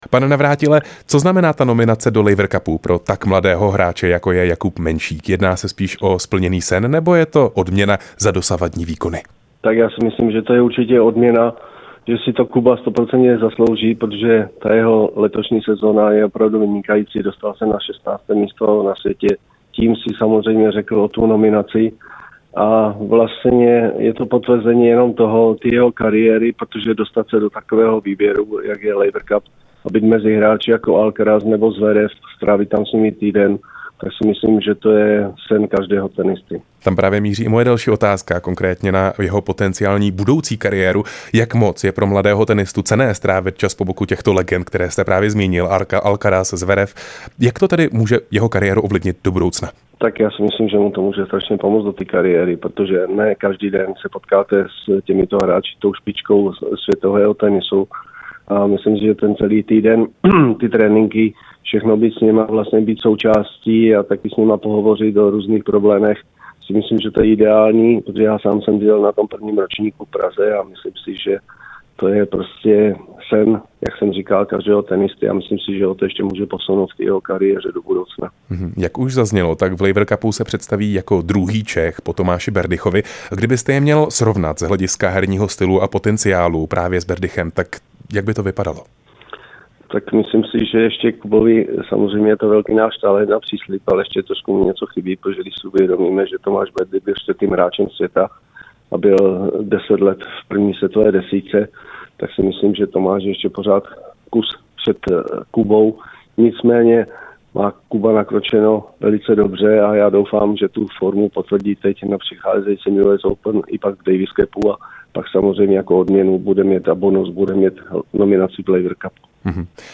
Rozhovor s bývalým tenistou a trenérem Jaroslavem Navrátilem